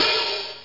Hihatopen Sound Effect
hihatopen.mp3